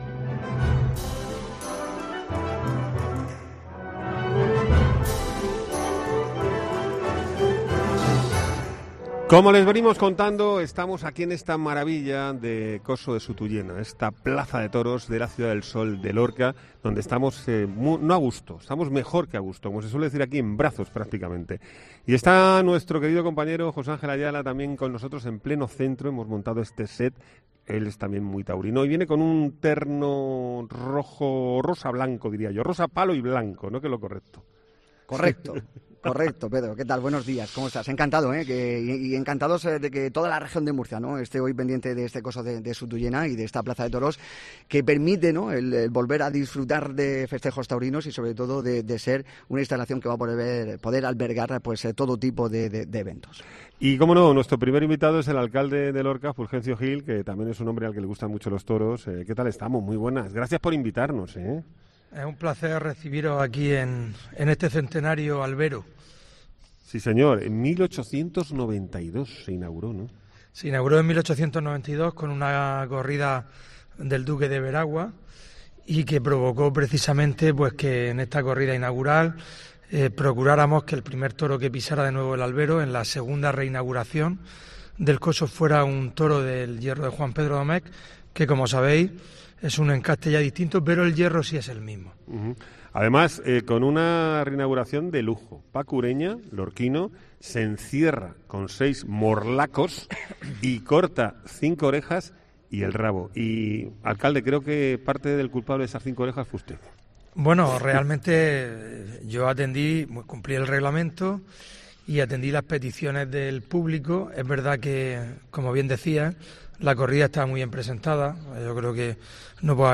Fulgencio Gil, alcalde de Lorca, explica que la corrida inicial de Sutullena ha dejado beneficios
El primer anfitrión ha sido el alcalde de Lorca, que está exultante con el retorno de la tauromaquia al Coso de Sutullena.